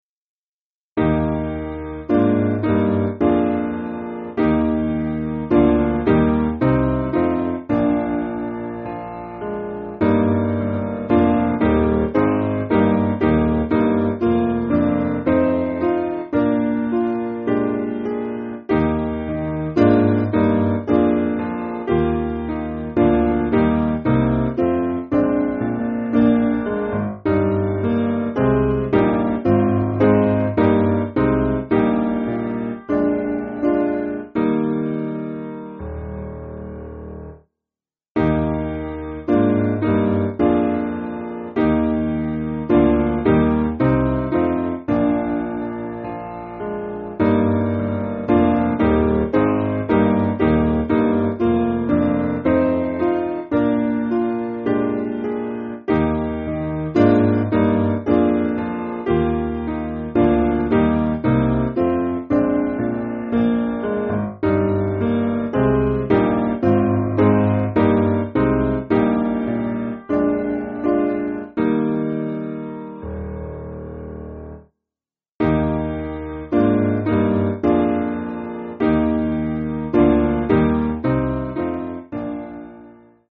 Simple Piano
(CM)   4/Eb